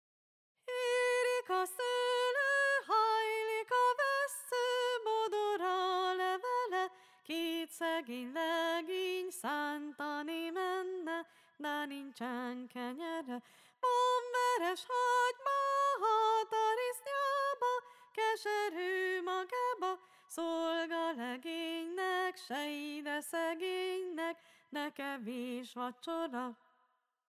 Az ALAPOK: Gyökereiben a X. század előtti népdalaink világába kalauzol, ahol gyakran látunk párhuzamot a természet és az ember belső világa között.